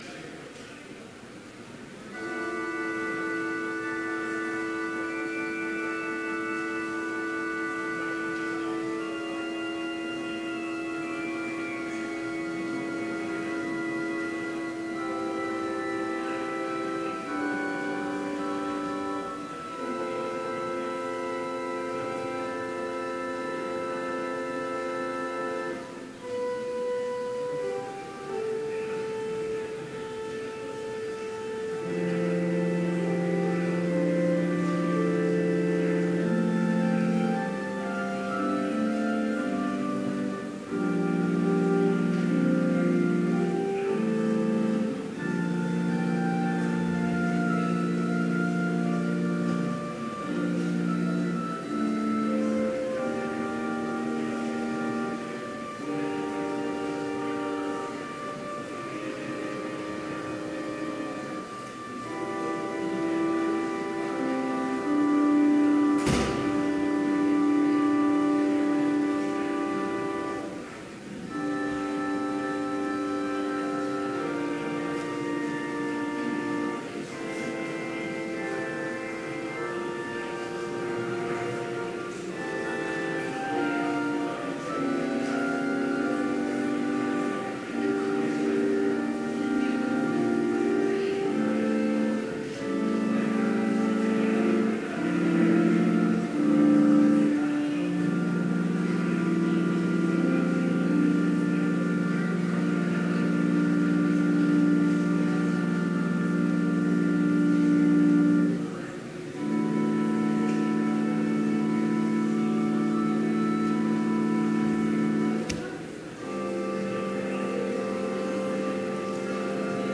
Complete service audio for Chapel - November 6, 2017